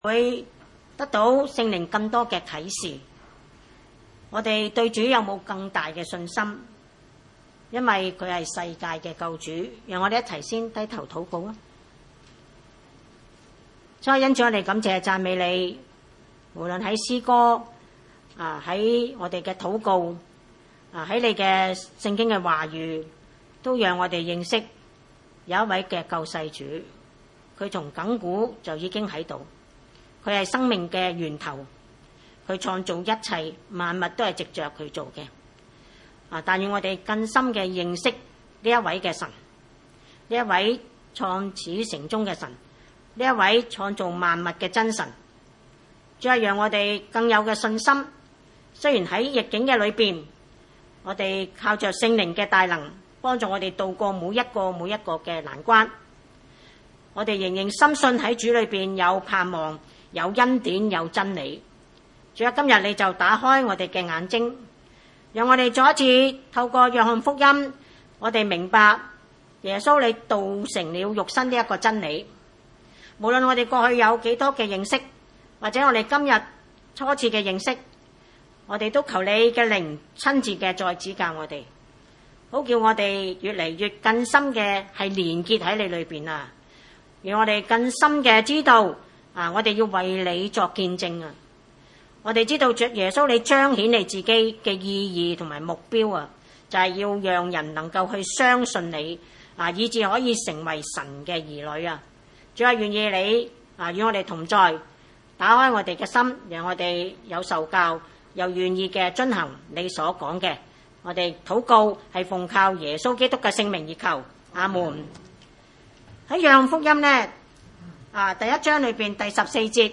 約翰福音一:1-18 崇拜類別: 主日午堂崇拜 1.